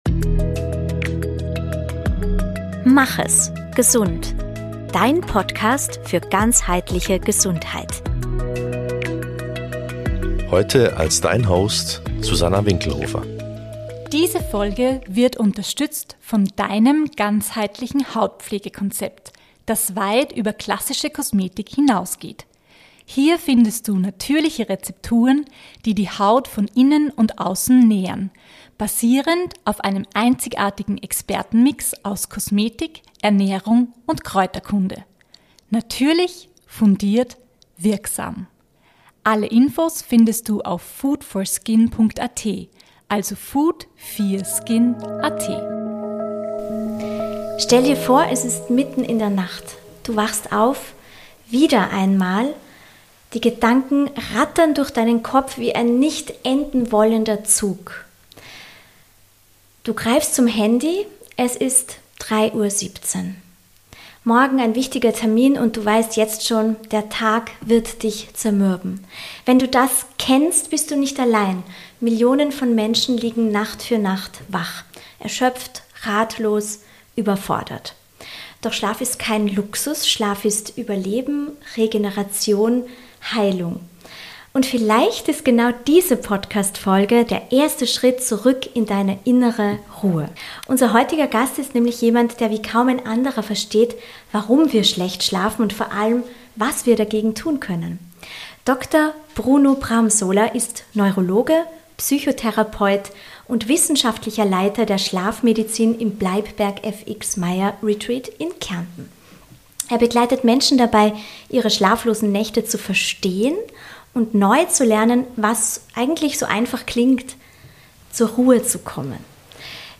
Ein Gespräch über die Nacht, die uns den Tag raubt. Und darüber, wie wir sie zurückerobern.